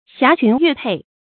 霞裙月帔 xiá qún yuè pèi
霞裙月帔发音